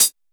BEAT HAT 01.WAV